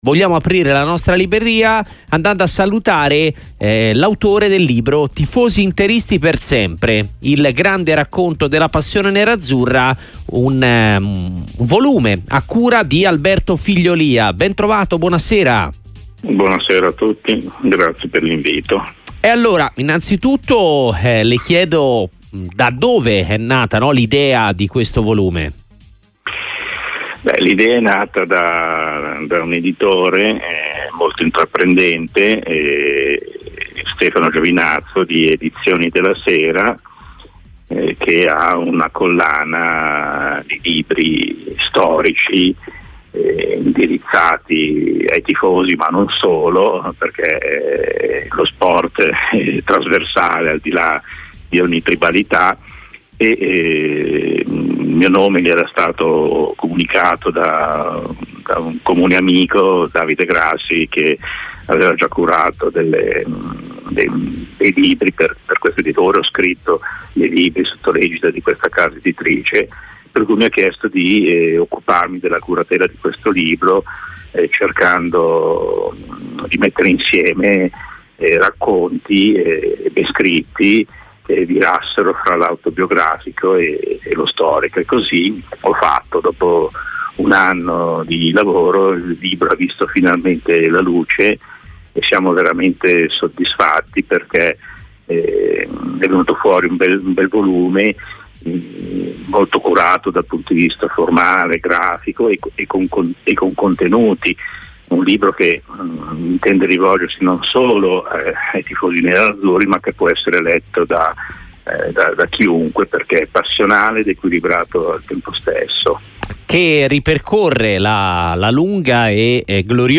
radiosportiva-tifosi-interisti.mp3